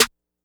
Cardiak Snare.wav